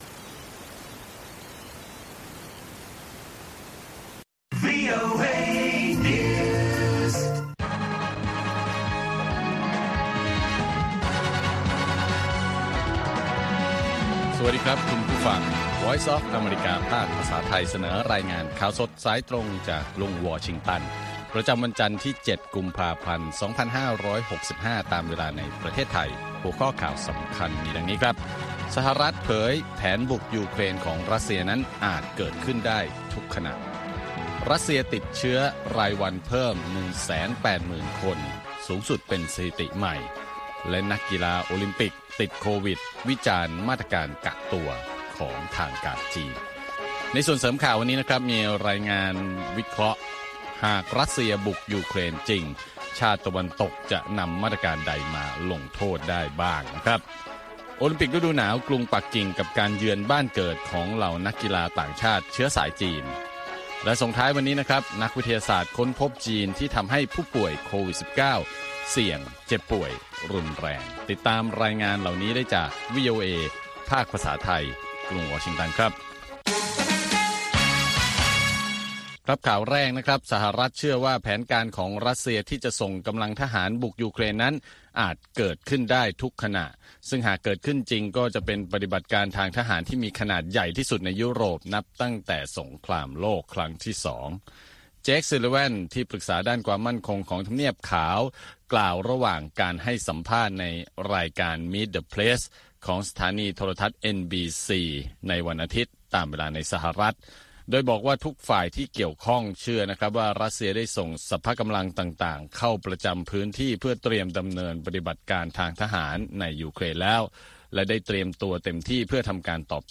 ข่าวสดสายตรงจากวีโอเอ วันจันทร์ที่ 7 กุมภาพันธ์ 2565